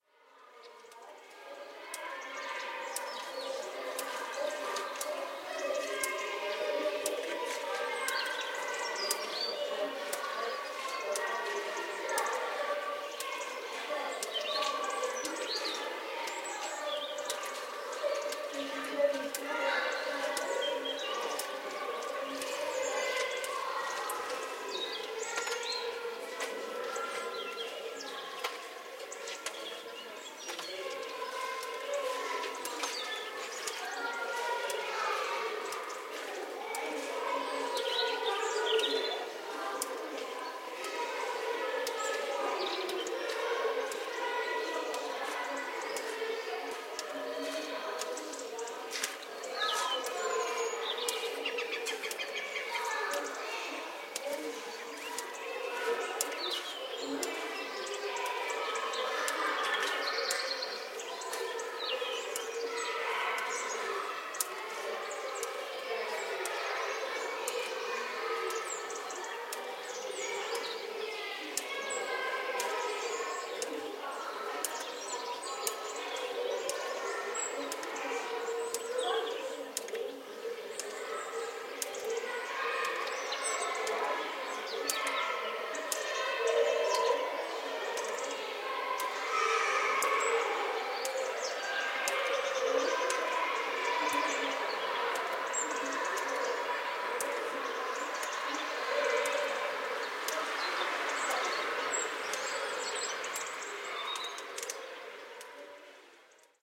Gravação do som de crianças na catequese, vindo de dentro da igreja de Santa Cruz da Trapa, misturado com o chilrear dos pássaros e os pingos da chuva. Gravado com Edirol R-44 e um microfone parabólico Tellinga PRO 7.